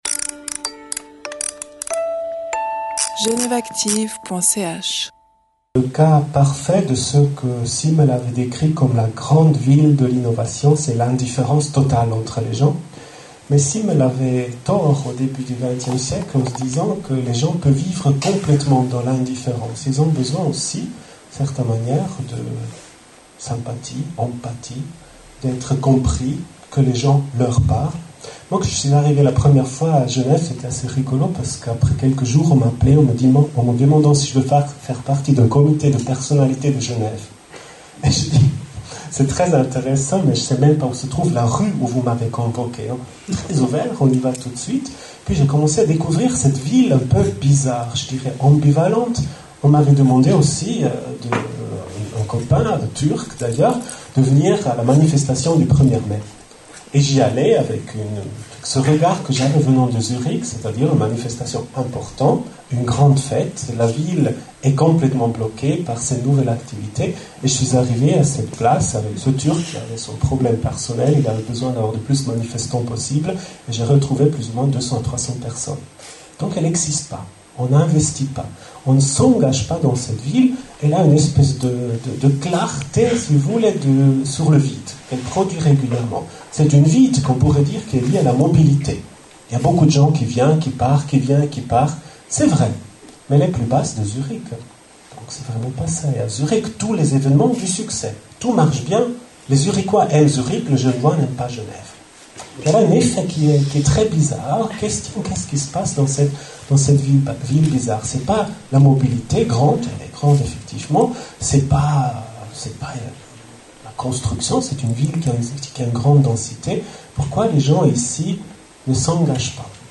La Fondation Braillard Architectes fête le centenaire du bureau d’architecture de Maurice Braillard par une série de conférences-débats sur les grands projets urbanistiques et architecturaux genevois.